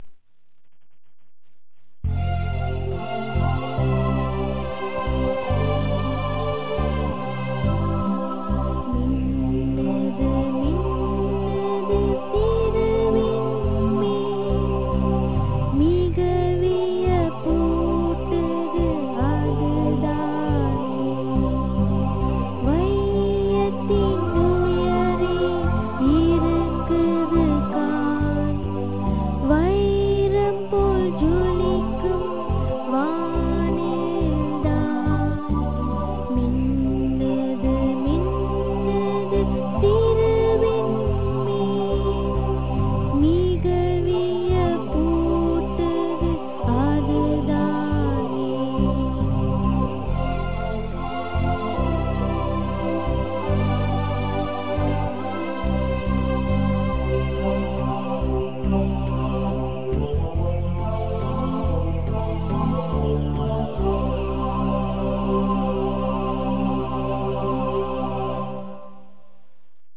In the final version, the sound recording and the singer's voice will be more professional.